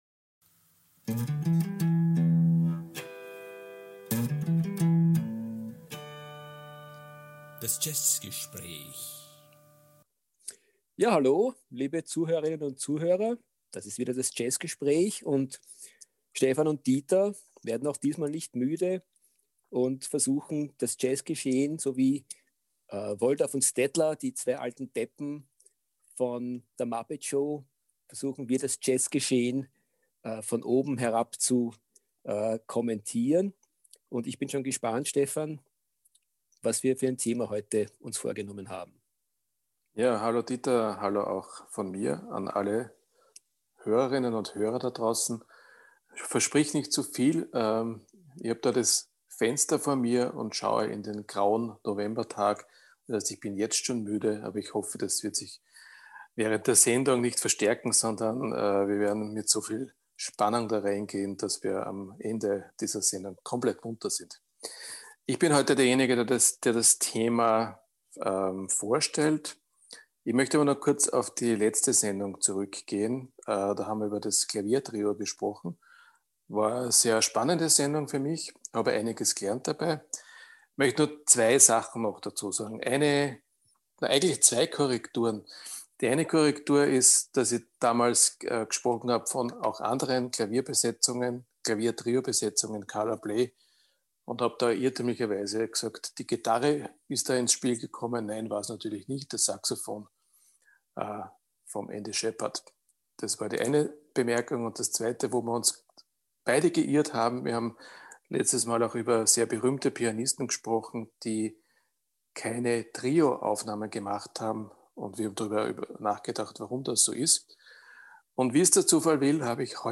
Letzte Episode Folge 4: Skandinavien 25. April 2021 Nächste Episode download Beschreibung Teilen Abonnieren In dieser Folge unterhalten wir uns über Jazz aus Skandinavien. Musik aus dem hohen Norden weist eine ganz eigene Ästhetik auf, und diese versuchen wir in unserem Gespräch einzufangen.